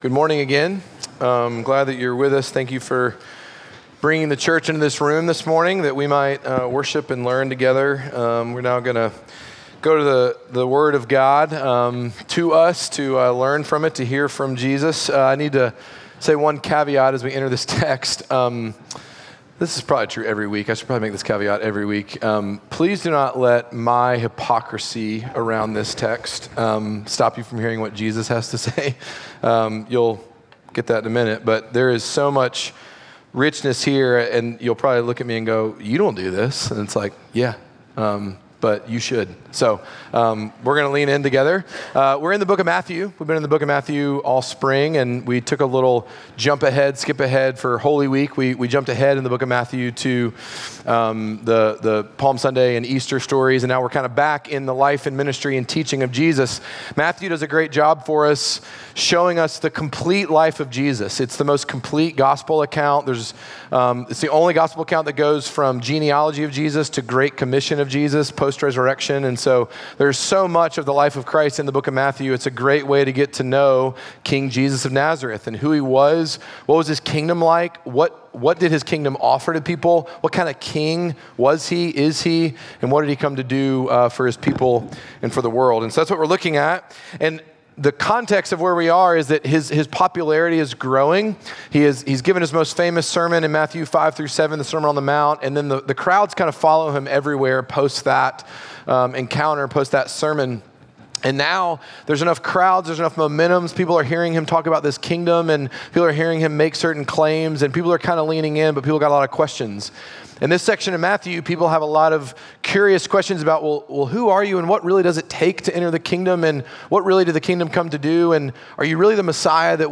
Midtown Fellowship 12 South Sermons Rest For Your Souls Apr 21 2024 | 00:44:20 1x 00:00 / 00:44:20 Subscribe Share Apple Podcasts Spotify Overcast RSS Feed Share Link Embed